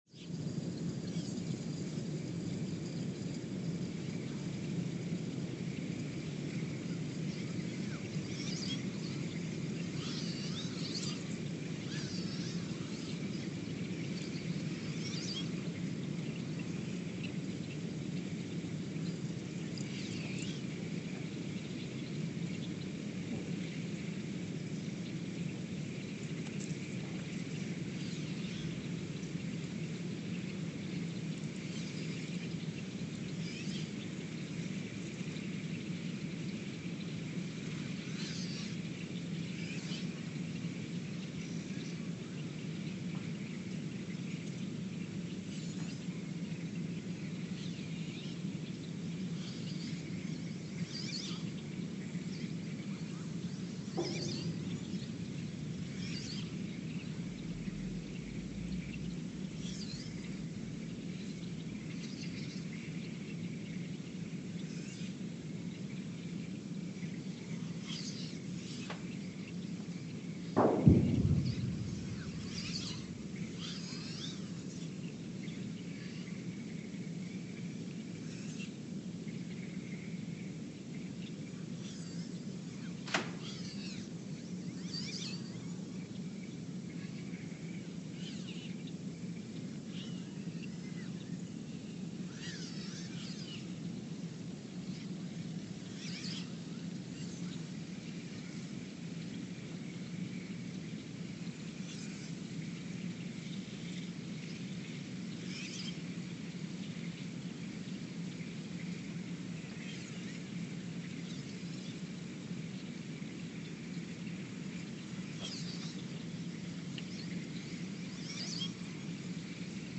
The Earthsound Project is an ongoing audio and conceptual experiment to bring the deep seismic and atmospheric sounds of the planet into conscious awareness.
Sensor : STS-1V/VBB Recorder : Quanterra QX80 @ 20 Hz
Speedup : ×900 (transposed up about 10 octaves)
Loop duration (audio) : 11:12 (stereo)